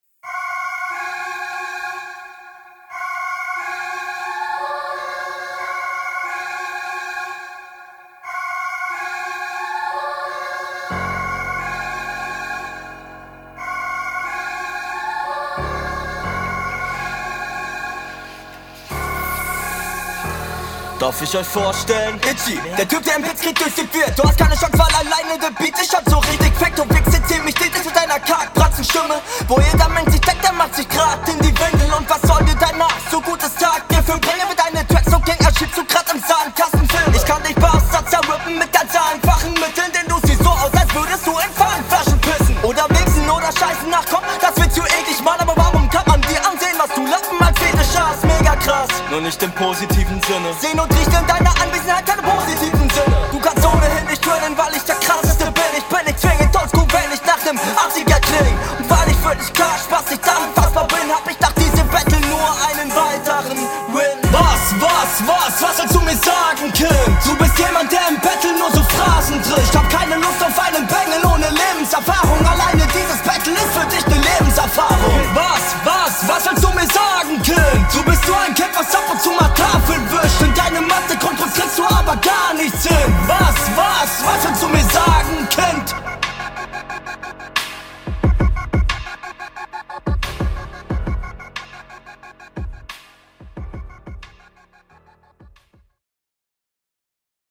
Audio ist super, der rappt sehr cool und die Qualität ist gut.